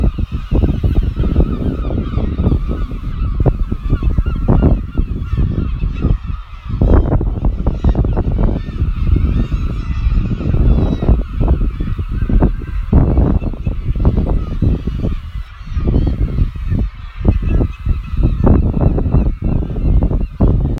Seabirds at Bow Fiddle Rock
These samples are only taken on a phone, so you’ll have to forgive the quality, but I hope they help to bring to life some of these wild and windswept places.
These days these strange outcrops are a haven for seabirds who cover every available surface, filling the air with their harsh, soaring crys and staining the rock white with guano.
seabirds.m4a